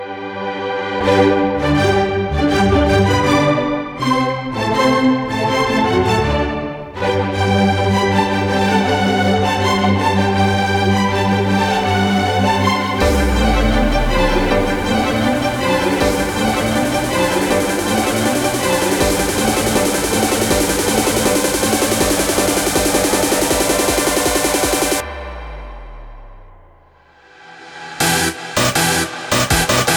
Жанр: Танцевальные / Хаус